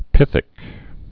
(pĭthĭk)